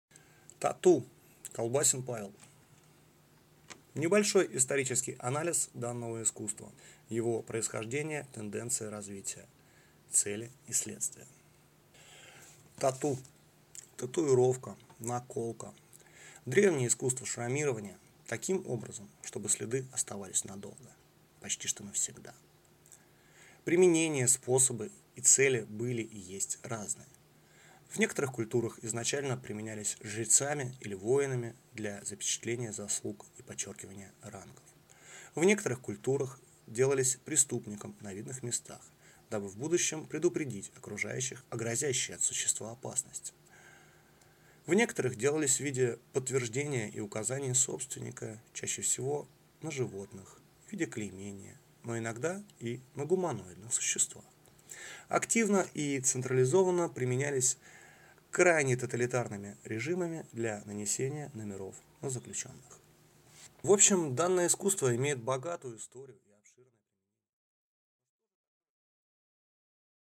Aудиокнига Тату